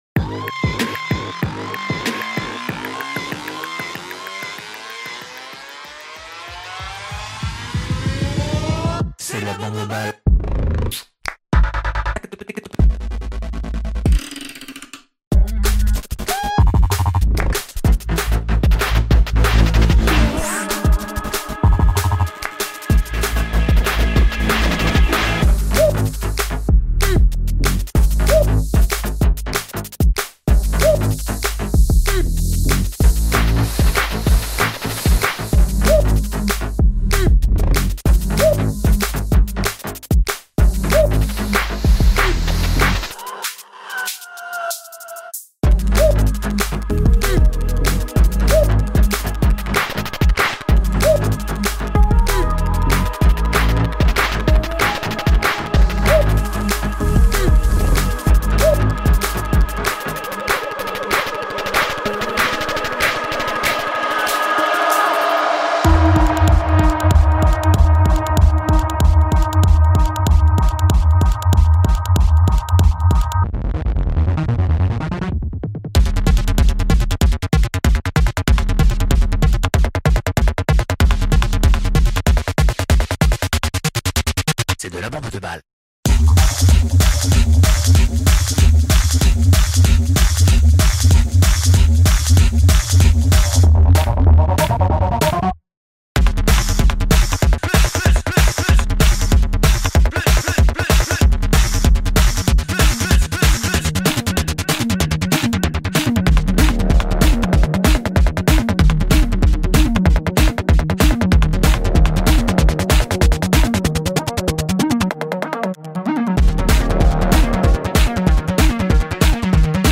BPM48-760
Audio QualityPerfect (High Quality)
Comments(FULL SONG)